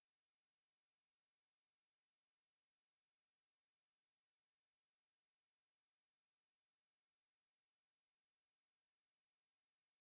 silence.ogg